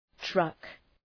Προφορά
{trʌk}